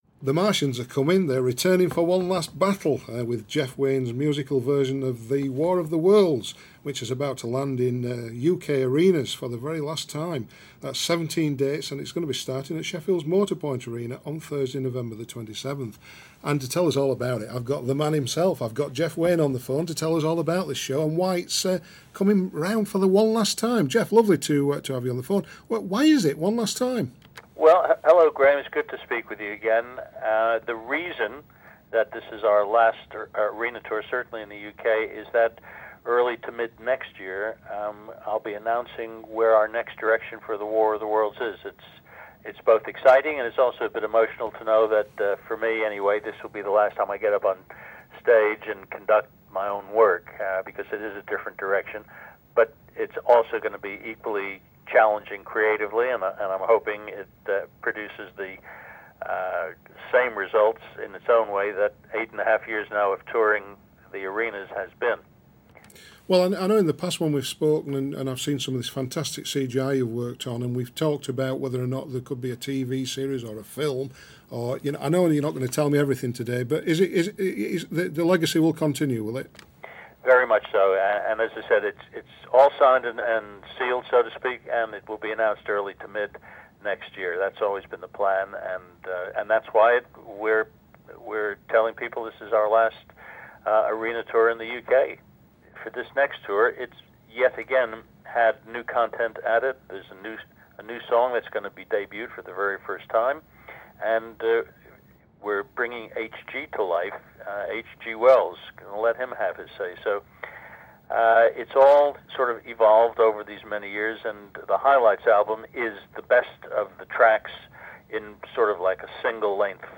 Interview: Jeff Wayne